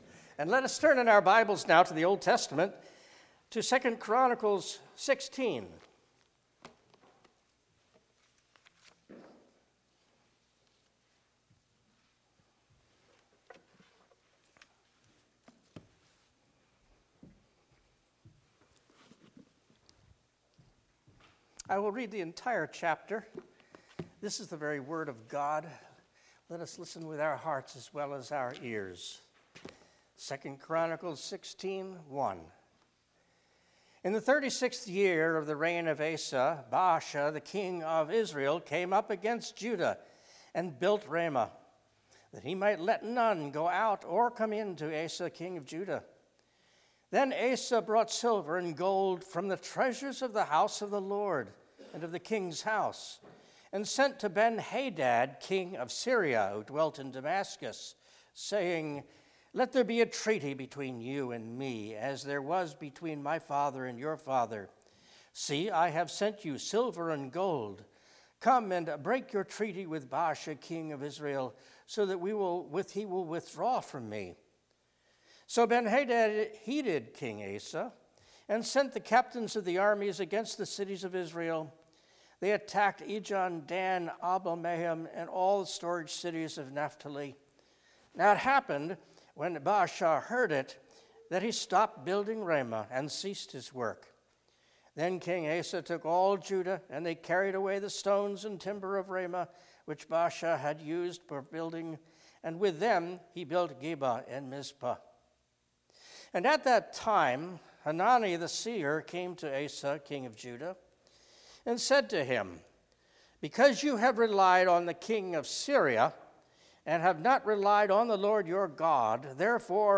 Passage: 2 Chronicles 16 Service Type: Worship Service